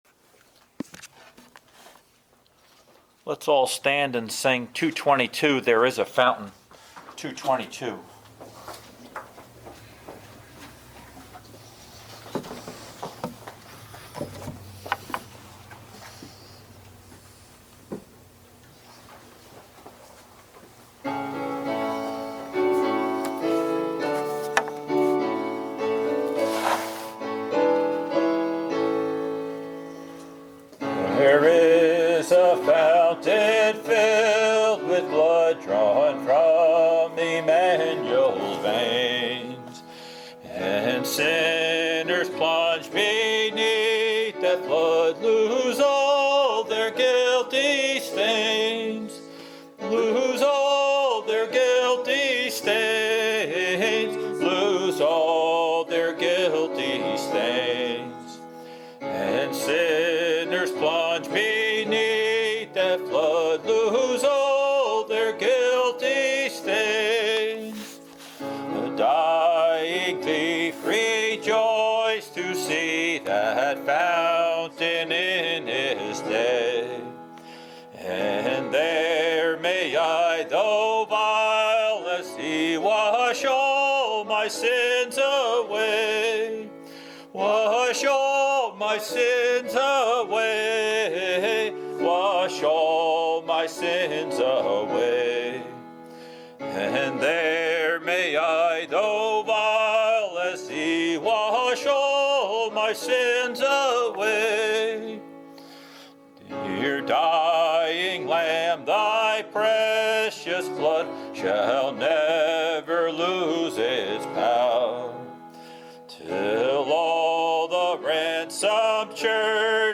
Christ, Our Glory and Covering | SermonAudio Broadcaster is Live View the Live Stream Share this sermon Disabled by adblocker Copy URL Copied!